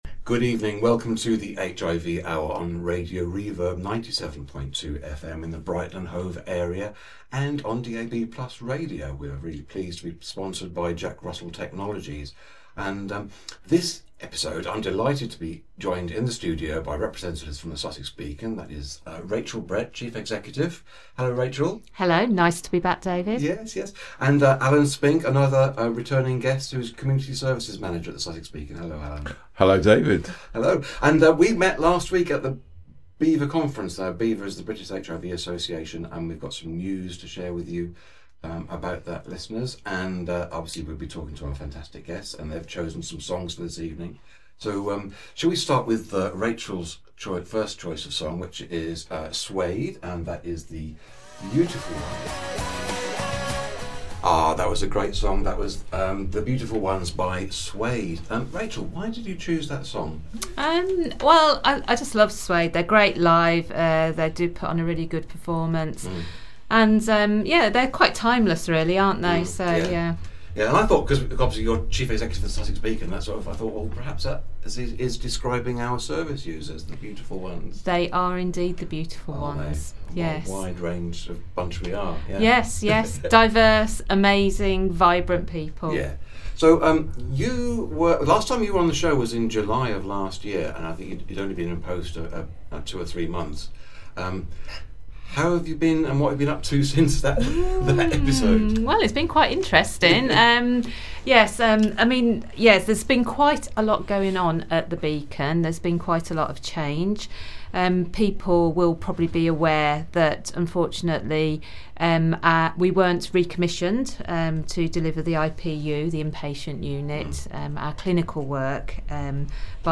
We also discuss highlights and Vox pops from the British HIV Association (BHIVA) Spring conference, which took place in Brighton the previous week.